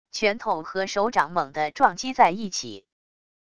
拳头和手掌猛地撞击在一起wav音频